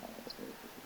tuollainen ehkä keltasirkku
tuollainen_ehka_keltasirkun_aani.mp3